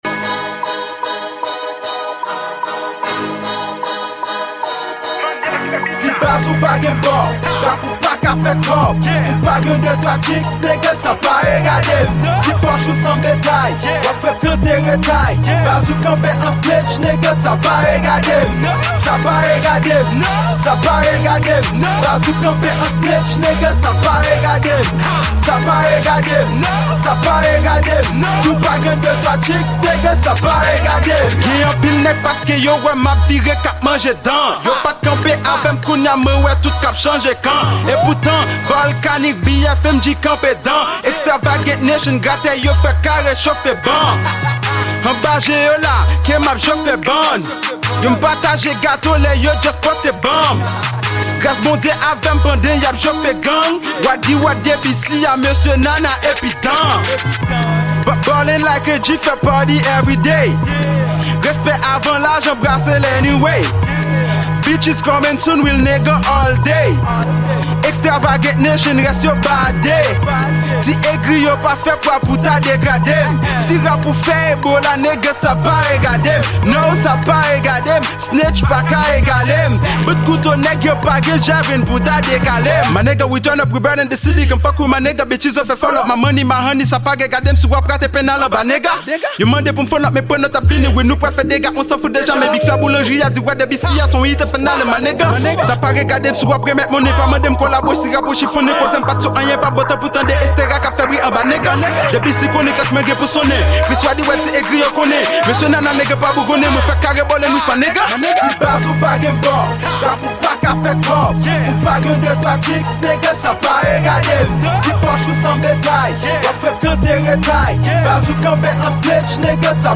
Genre: Rap Kreyol.